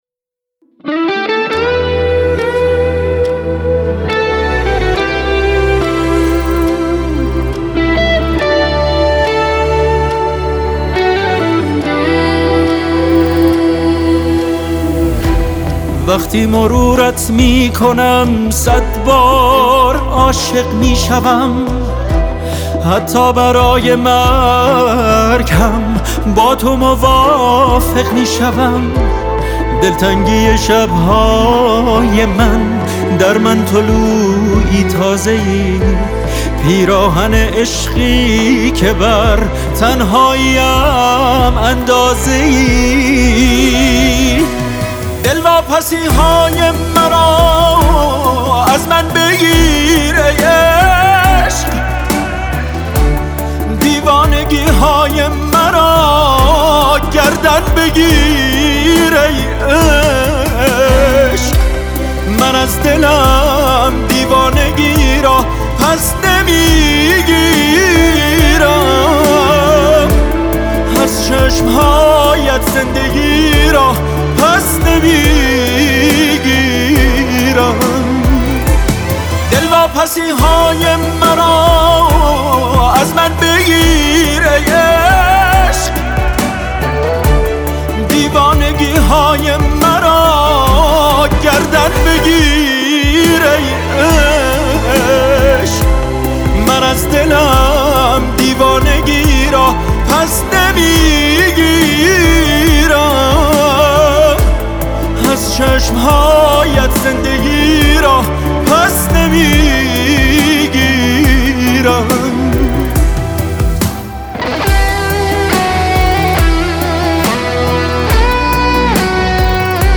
آهنگ پاپ آهنگ سنتی آهنگ تلفیقی